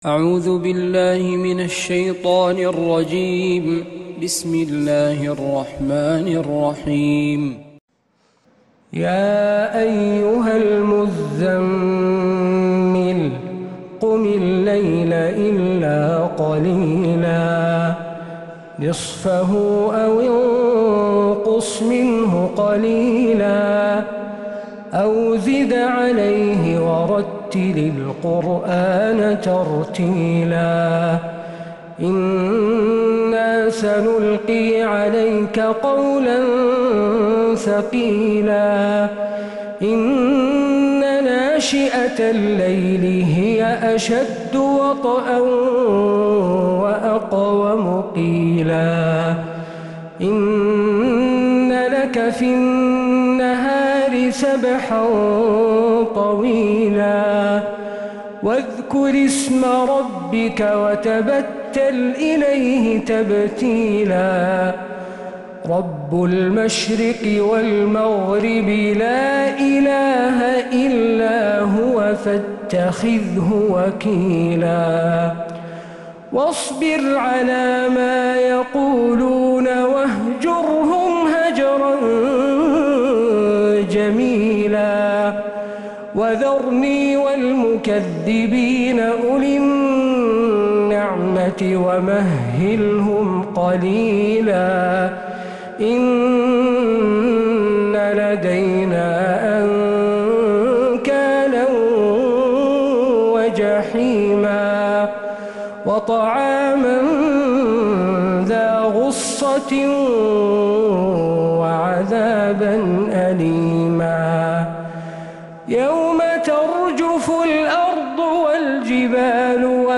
سورة المزمل كاملة من عشائيات الحرم النبوي